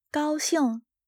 gāoxìng
ガオ シン